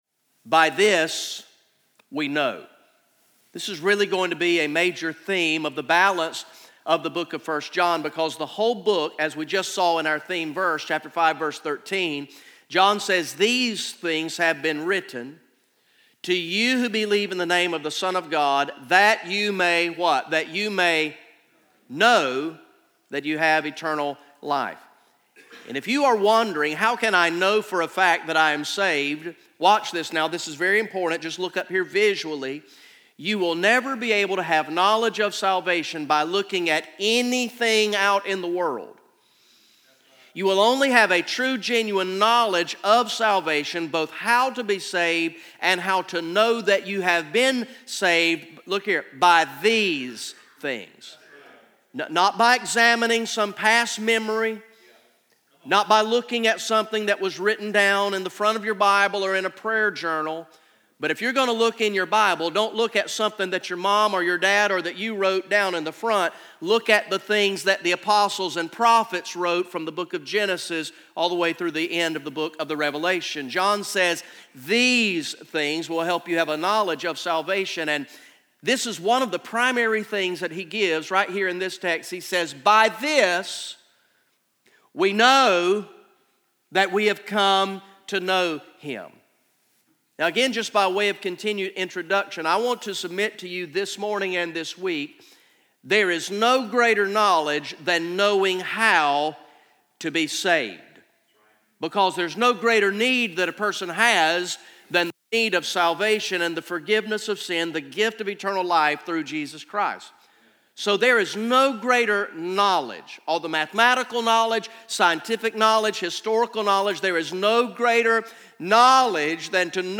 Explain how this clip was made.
Message #03 from the ESM Summer Camp sermon series through the book of First John entitled "You Can Know"